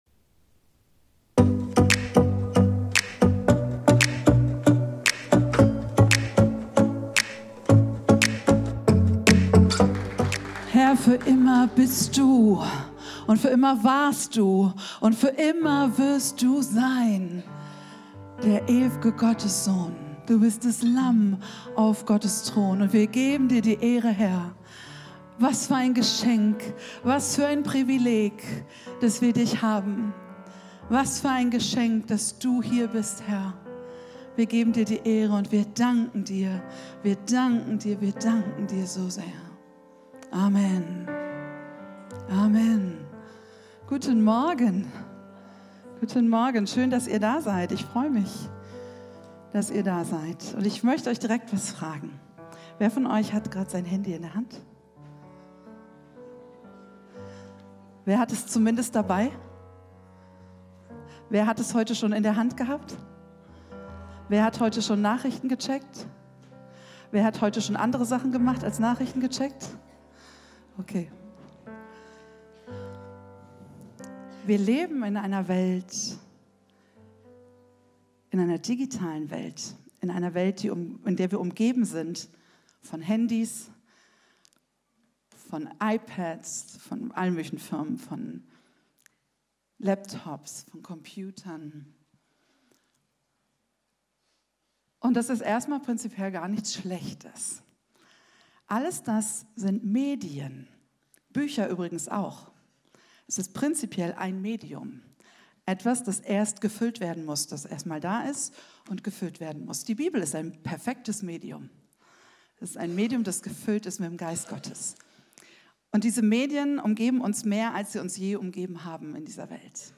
Video und MP3 Predigten
Kategorie: Sonntaggottesdienst Predigtserie: Im Rhythmus seiner Gnade leben